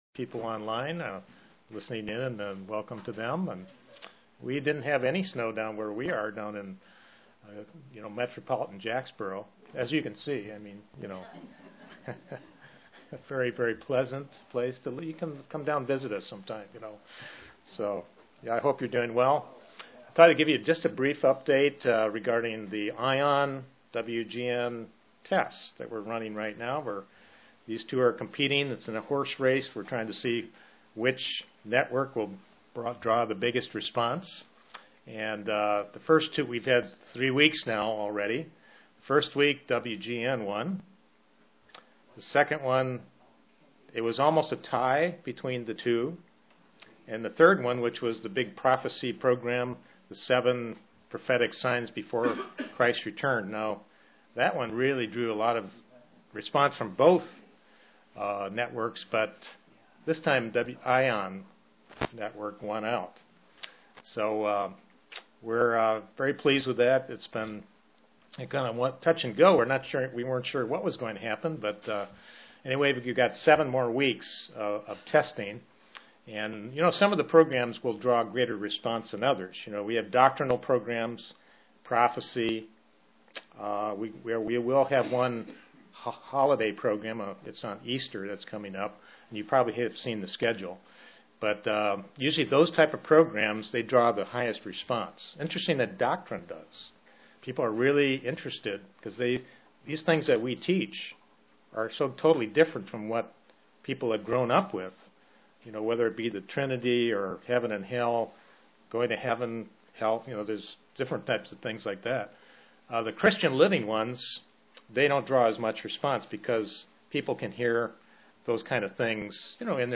Given in London, KY
Print do we examine ourselves properly UCG Sermon Studying the bible?